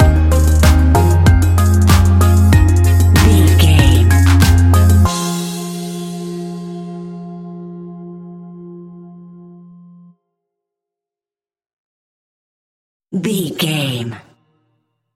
Ionian/Major
F♯
house
electro dance
synths
techno
trance
instrumentals